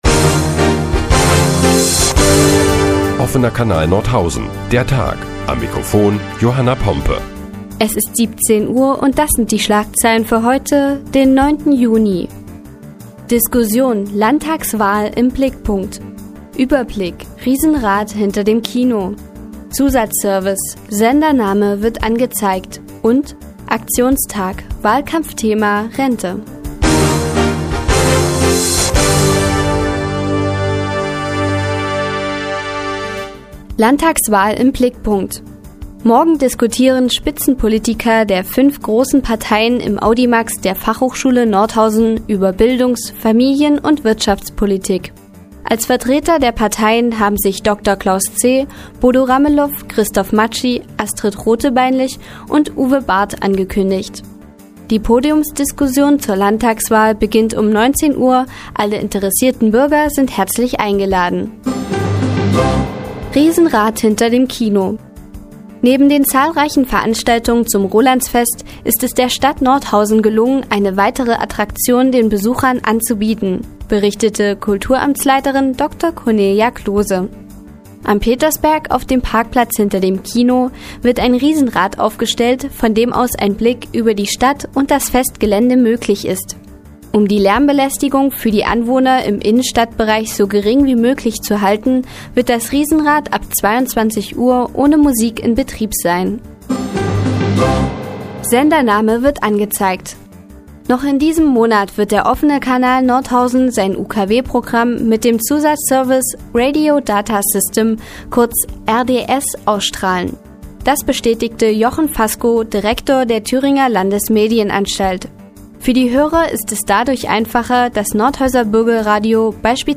Die tägliche Nachrichtensendung des OKN ist nun auch in der nnz zu hören. Heute geht es unter anderem um eine Podiumsdiskussion zur Landtagswahl im Audimax der FH und um ein Riesenrad hinter dem Kino.